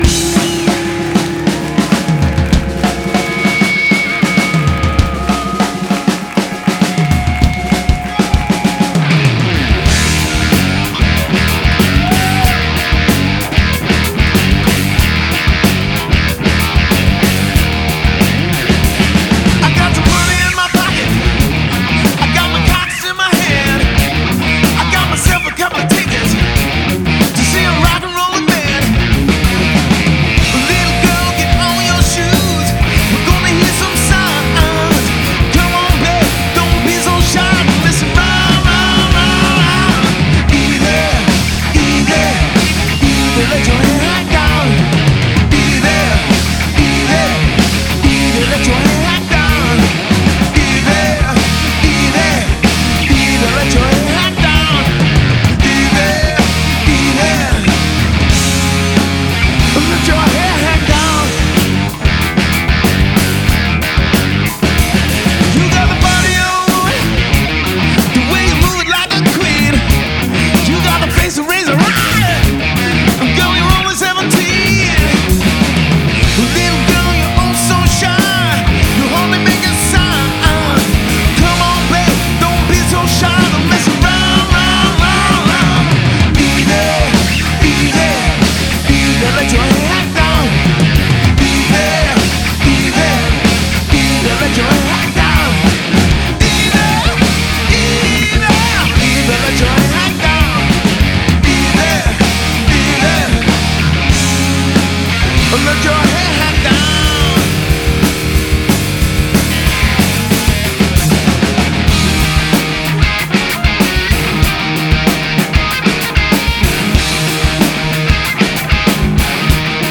straight ahead classic 70’s Rock